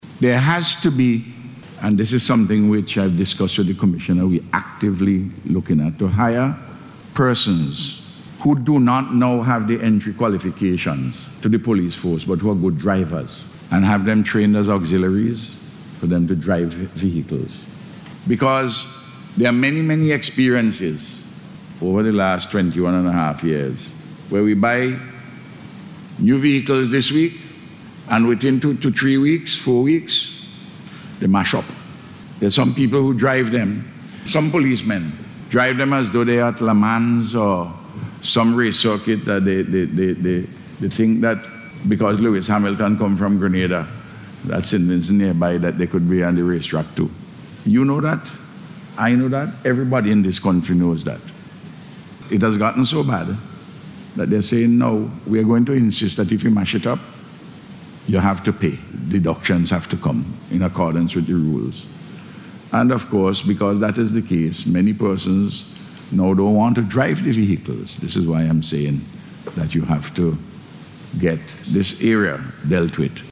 In response to a question in Parliament on Monday, the Prime Minister said they are looking at the possibility of hiring skilled drivers to the Royal St Vincent and the Grenadines Police force.